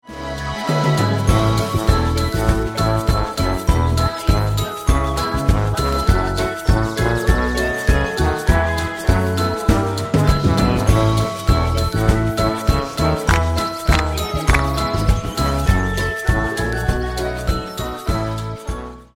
wesoła i żywiołowa piosenka dla dzieci
Wersja instrumentalna z chórkami i linią melodyczną: